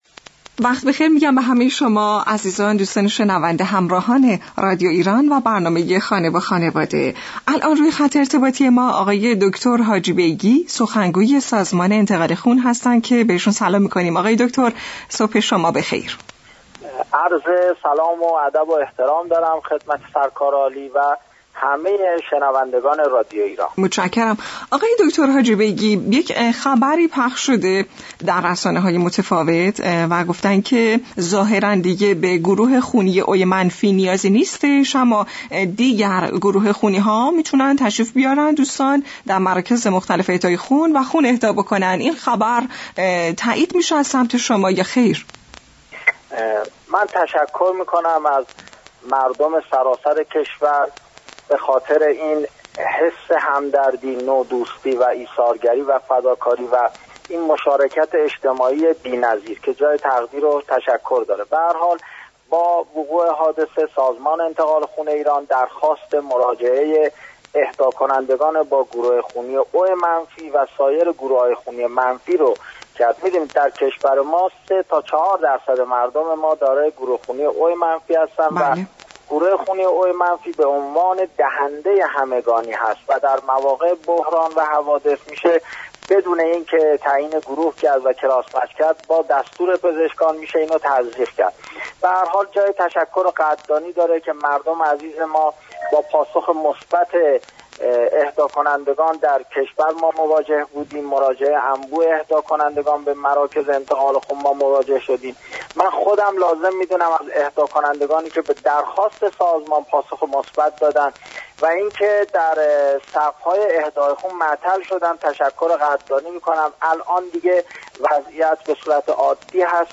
گفت و گو با رادیو ایران